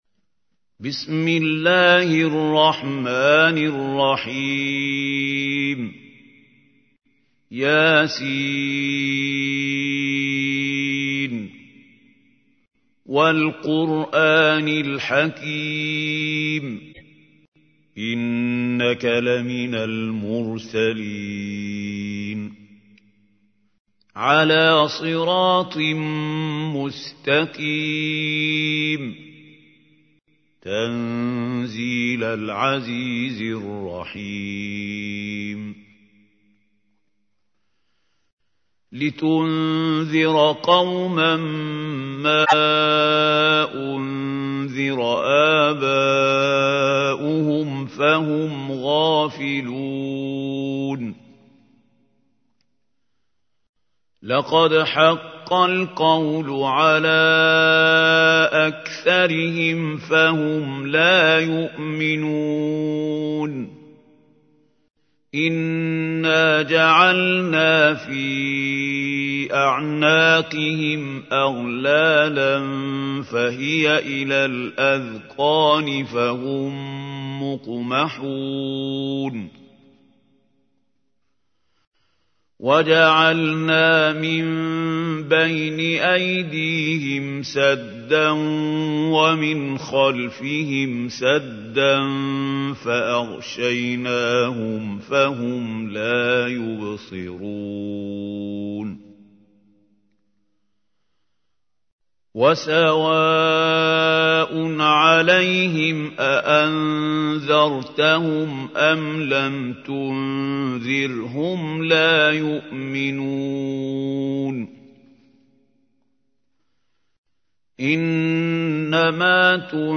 تحميل : 36. سورة يس / القارئ محمود خليل الحصري / القرآن الكريم / موقع يا حسين